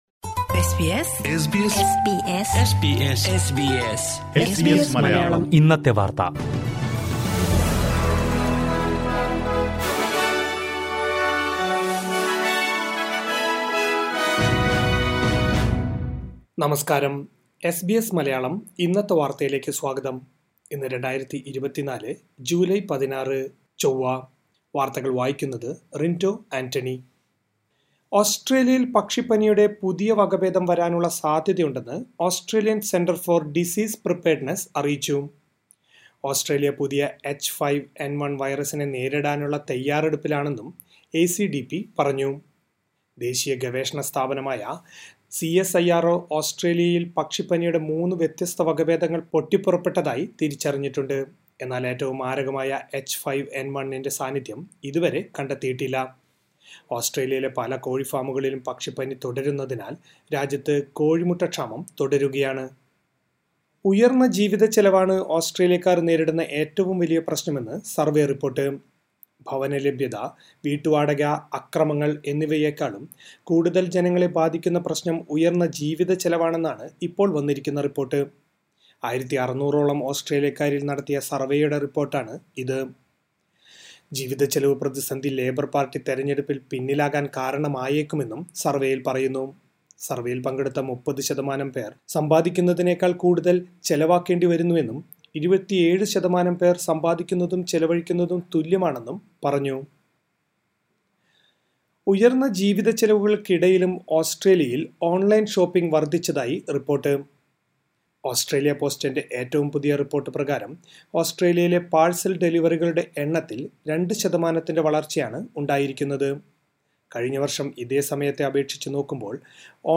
2024 ജൂലൈ 16ലെ ഓസ്‌ട്രേലിയയിലെ ഏറ്റവും പ്രധാന വാര്‍ത്തകള്‍ കേള്‍ക്കാം...